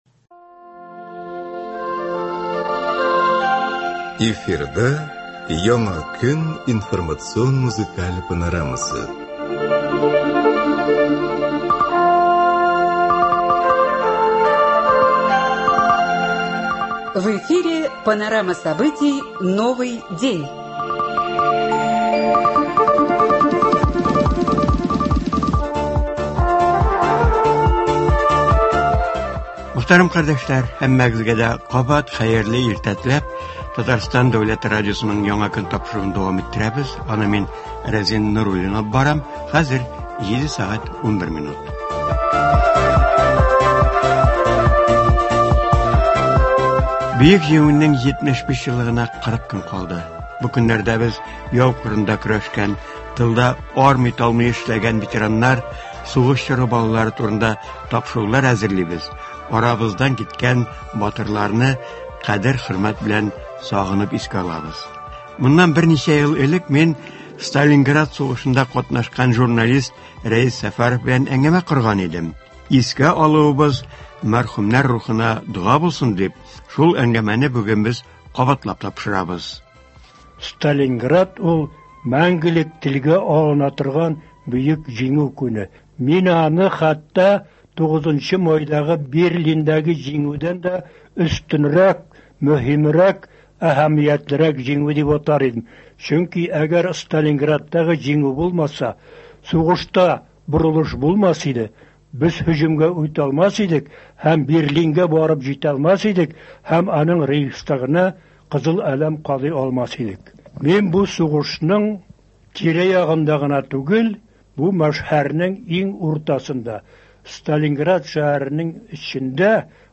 әңгәмә кабатлап бирелә, шулай ук Чаллы төбәгеннән әзерләнгән репортажлар яңгырый.